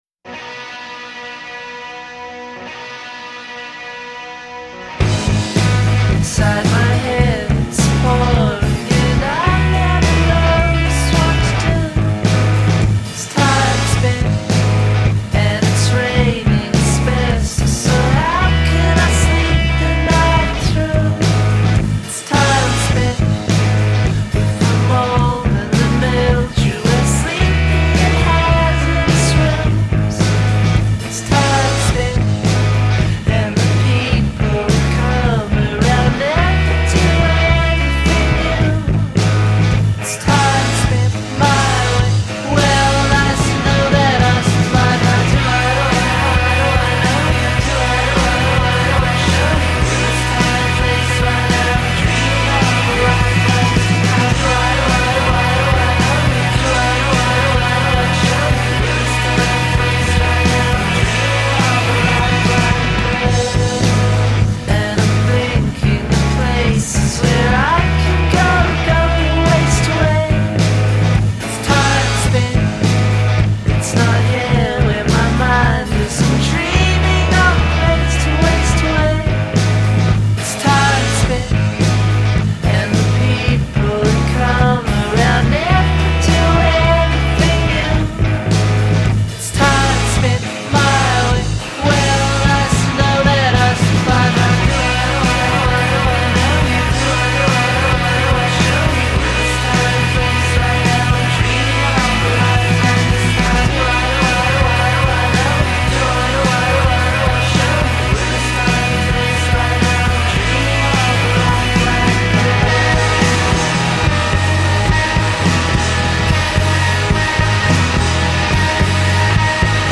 Indie Indie pop Indie rock